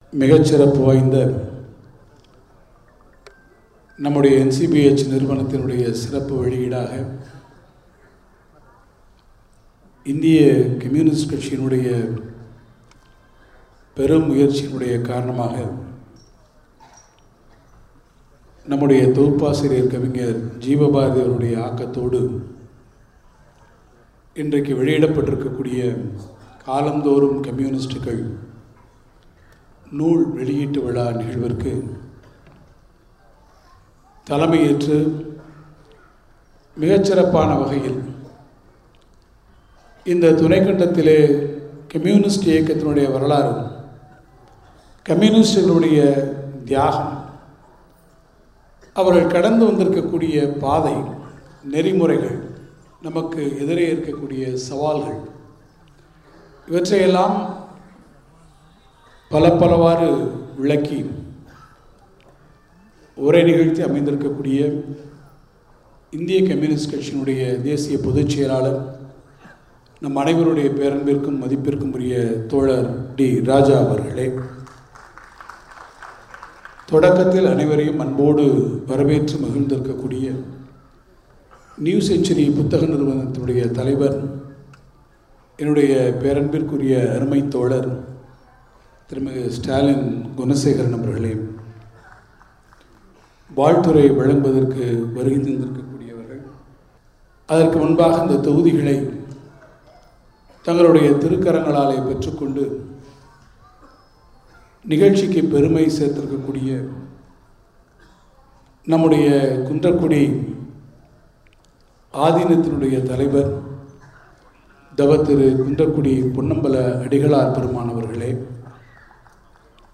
‘காலம் தோறும் கம்யூனிஸ்டுகள்’ நூல் வெளியீட்டு விழா சென்னையில் நடைபெற்றது. இதில் முதலமைச்சர் மு.க.ஸ்டாலின் கலந்துகொள்ள இயலாததால், அவர் சார்பாக நிதியமைச்சர் தங்கம் தென்னரசு கலந்துகொண்டு நூலின் இரண்டு தொகுதிகளையும் வெளியிட்டு சிறப்புரையாற்றினார்.